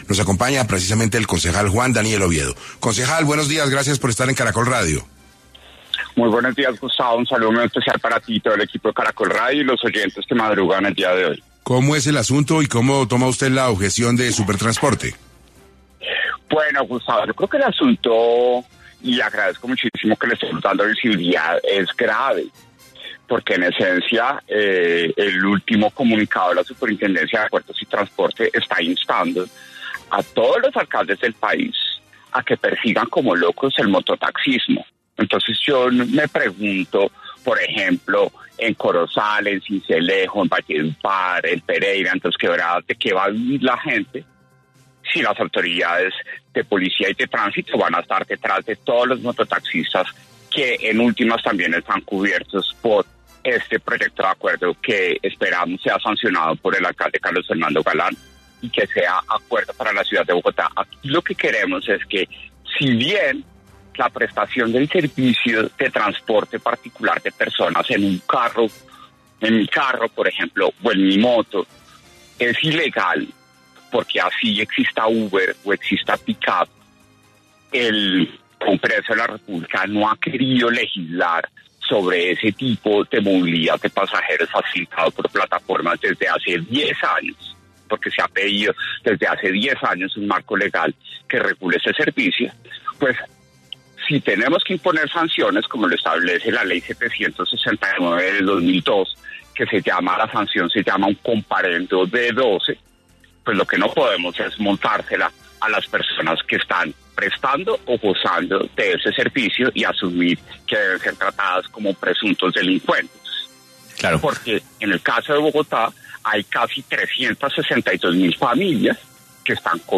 Juan Daniel Oviedo, concejal, habló en 6AM, sobre los señalamientos de Supertransporte ante el proyecto en el Concejo que ordenaría “incumplir la ley de tránsito y de transporte”
Ante esto, durante el programa 6AM del martes, 10 de diciembre, se conectó el concejal, Juan Daniel Oviedo, quien impulsa la iniciativa que busca establecer buenas prácticas para el transporte público no autorizado.